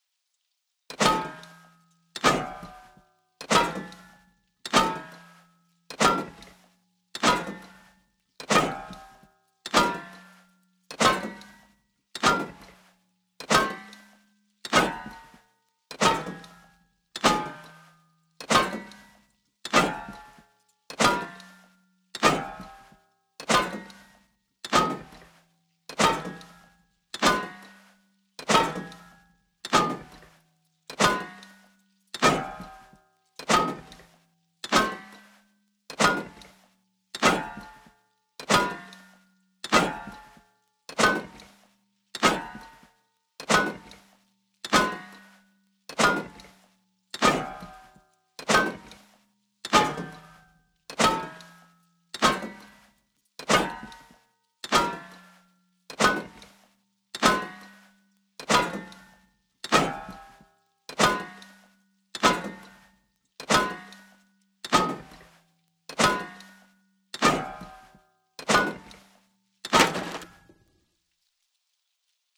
Salvaged Axe On Sheet Metal Wall